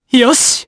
Arch-Vox_Happy4_jp_b.wav